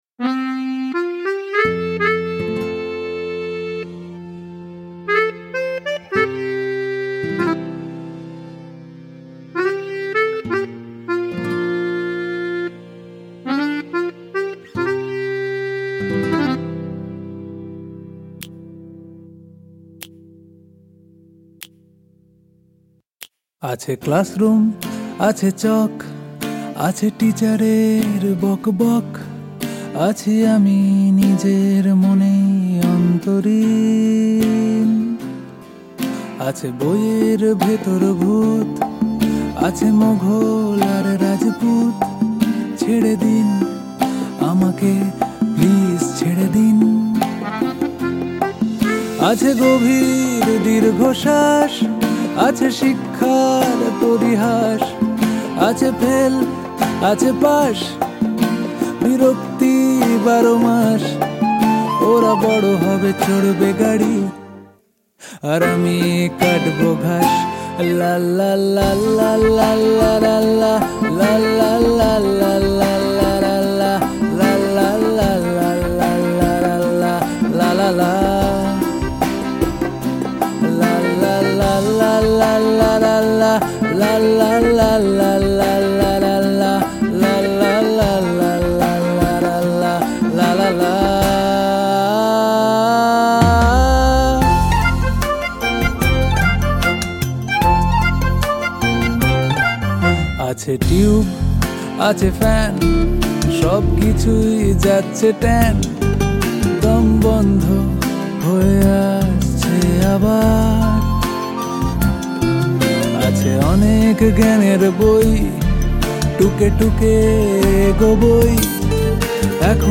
Guiatr Scale : E